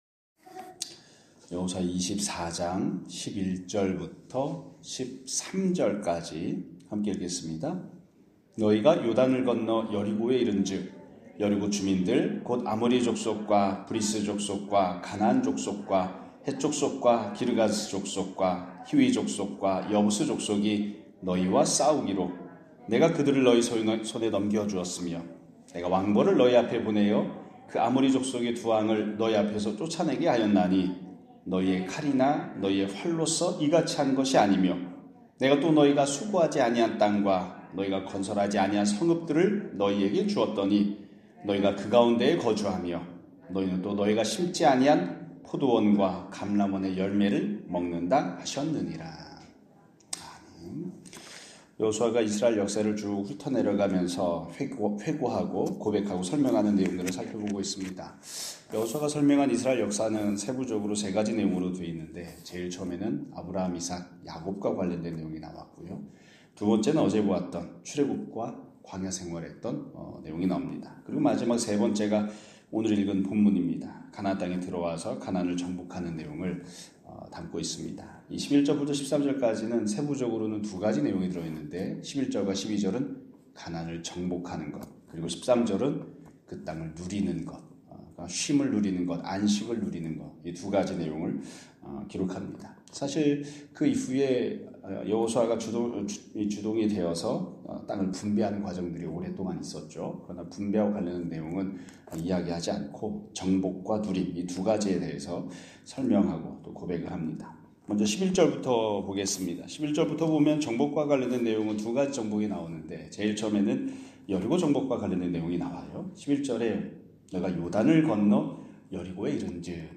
2025년 2월 25일(화요일) <아침예배> 설교입니다.